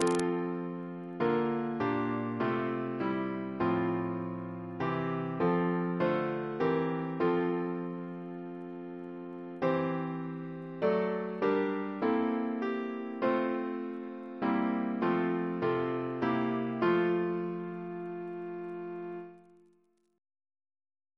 Double chant in F Composer: Edwin Edwards (1830-1907) Reference psalters: ACB: 217; ACP: 325; H1940: 694; PP/SNCB: 69